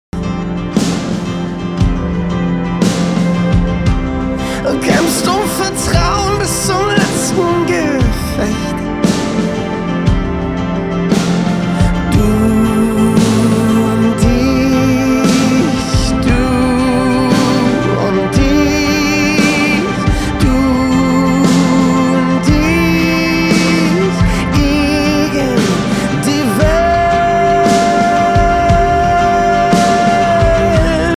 Gesang, Gitarre, Klavier und Backings
eine tief emotionale Atmosphäre